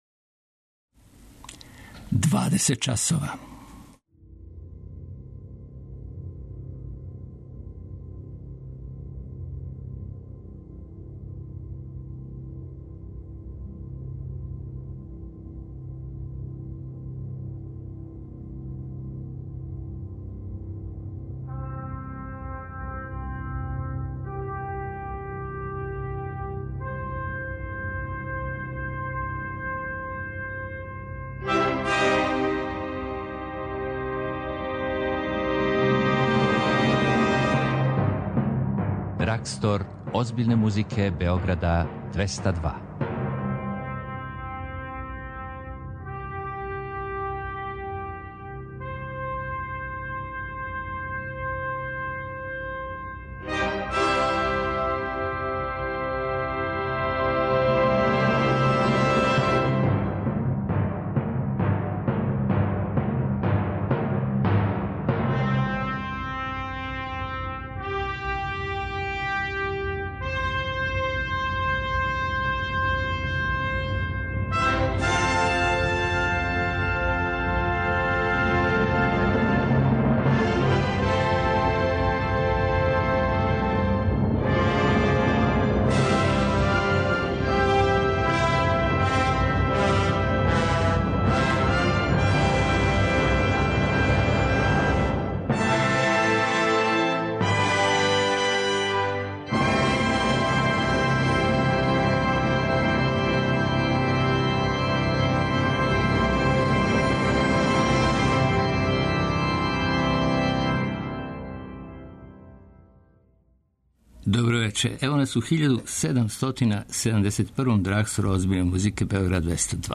Драгстор озбиљне музике Београда 202 већ 3 деценије промовише класичну музику, њене "хитове" и највеће ауторе, испуњава жеље слушалаца, директнo преноси и организује концерте.